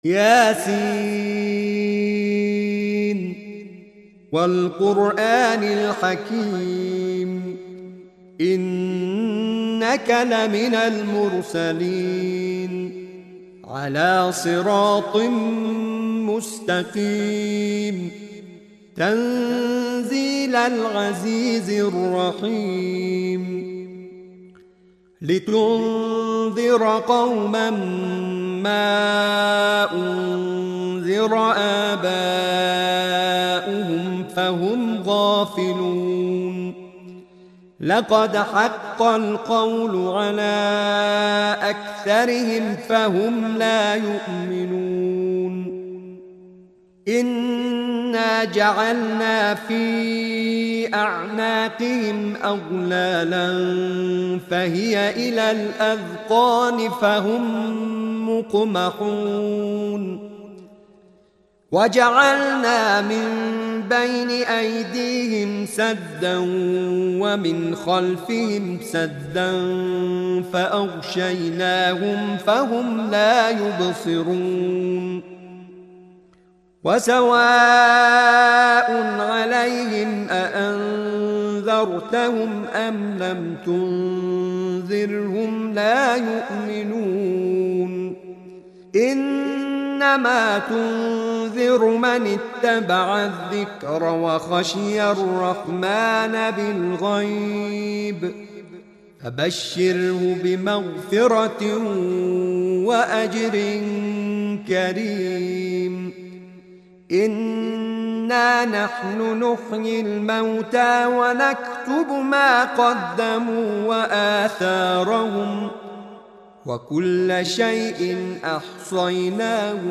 سوره مبارکه یس آیات 1 تا 23/ نام دستگاه موسیقی: رست